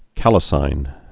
(kālĭ-sīn, -sĭn, kălĭ-)